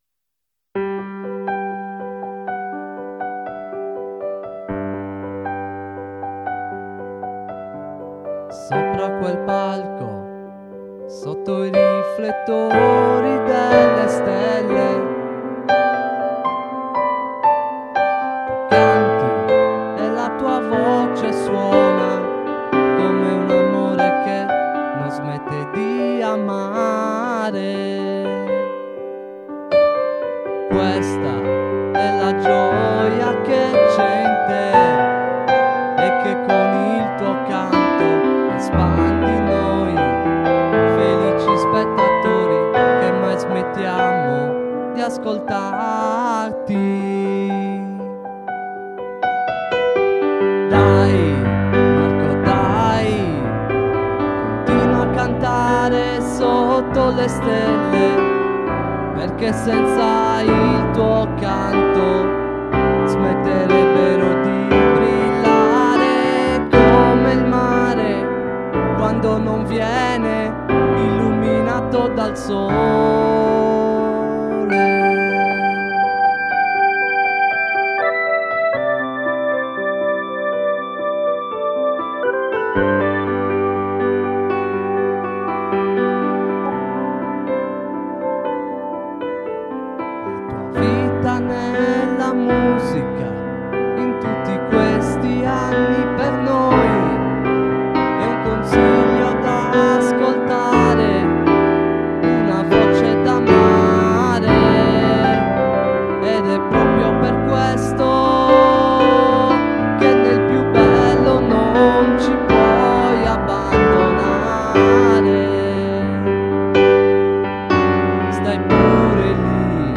versione cantata al pianoforte
voce
pianoforte